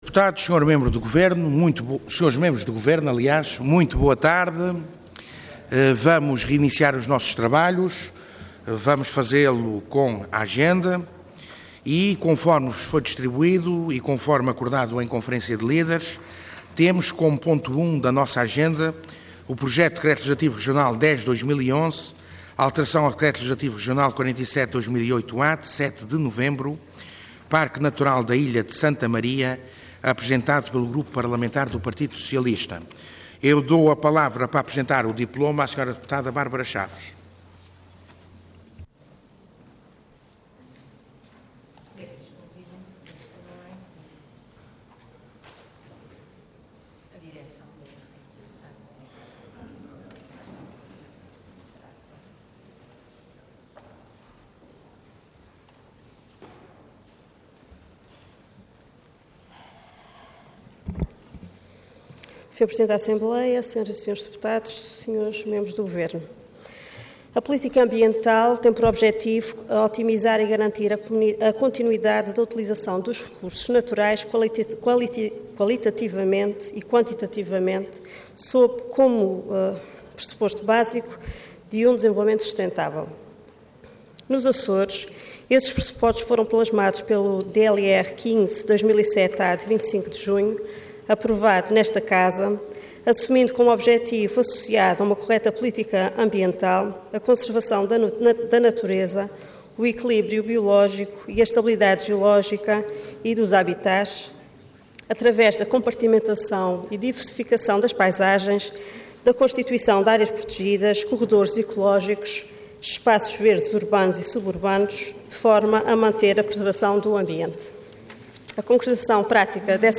Intervenção Projeto de Decreto Leg. Orador Bárbara Chaves Cargo Deputada Entidade PS